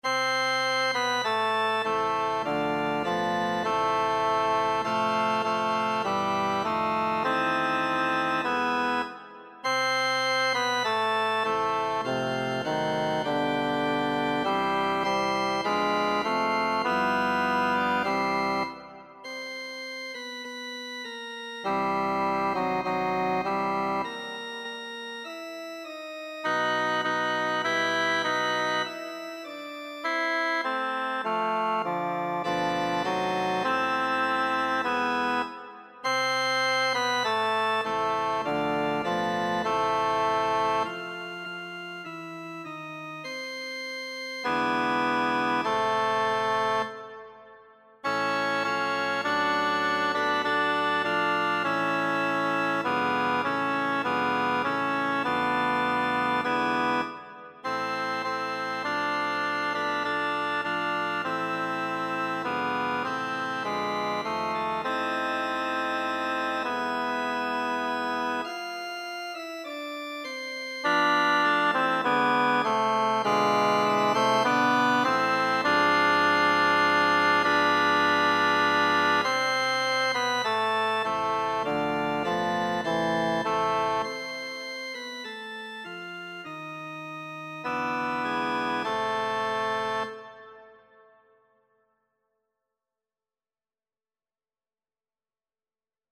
Tenor
abendlich-schon-rauscht-der-wald-tenor.mp3